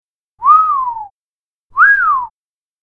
海外映画等で美しい女性が現れ、男性の目が釘付けとなり、その男性が思わず吹いてしまう口笛の音（2パターン）。